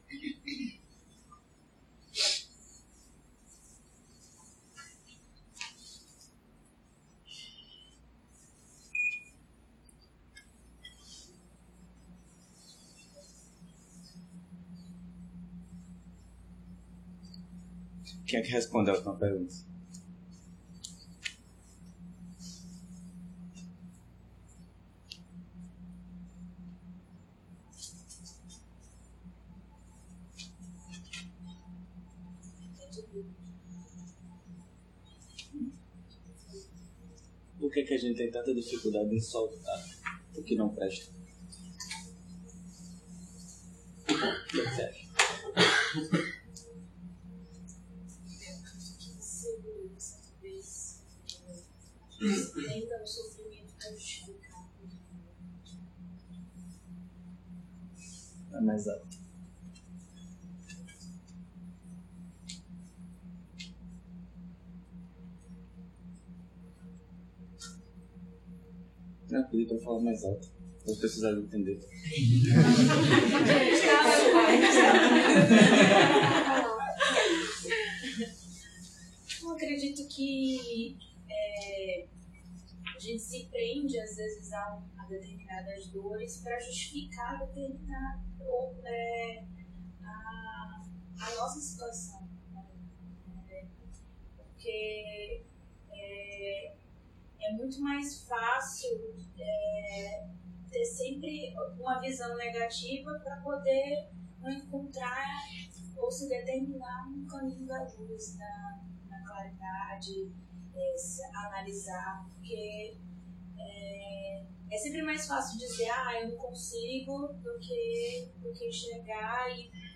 Encontro ocorrido no CEBB Recife, sala encruzilhada em 14 de fevereiro de 2019. Ciclo de estudo: A operação da mente na visão budista através dos 12 elos.